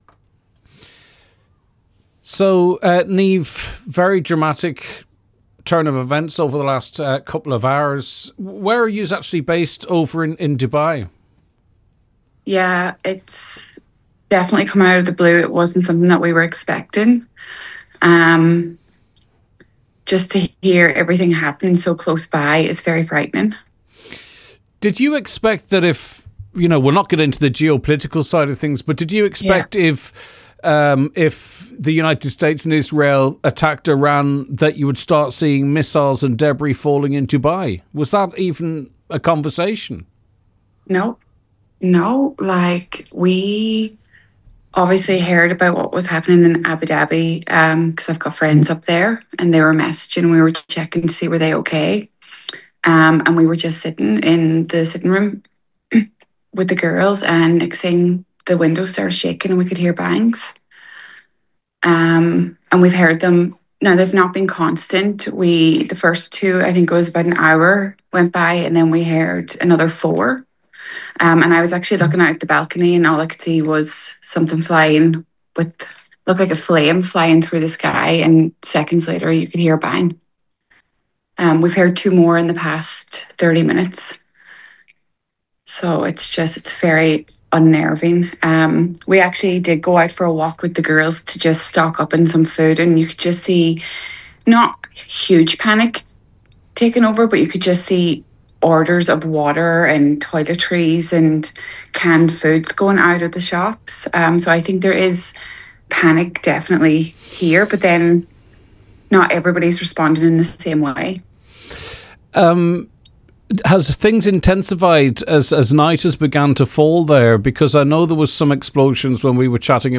Donegal woman describes 'surreal' scenes as Iran fires missiles at Dubai - Highland Radio - Latest Donegal News and Sport